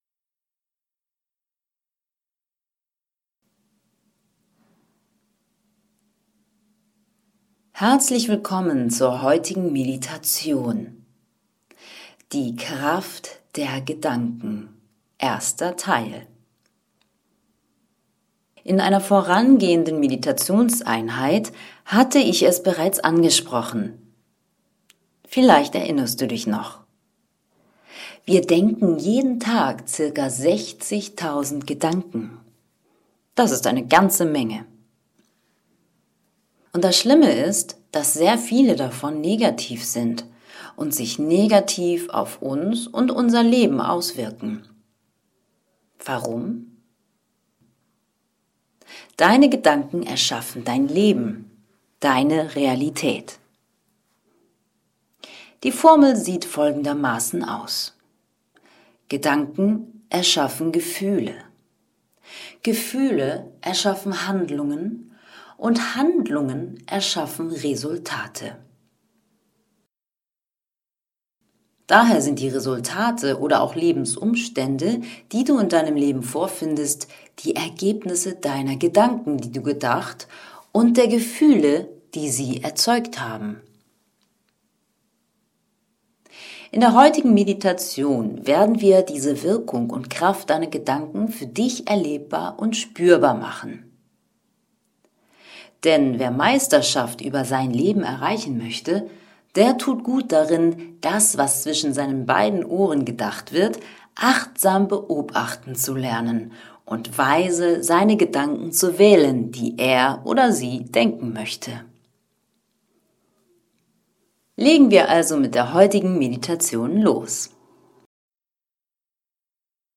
Die Kraft der Gedanken Teil 1 und Übung „Fantasiemeditation“ (Audio)
Meditation4_DieKraftderGedanken_Fantasiemeditation.mp3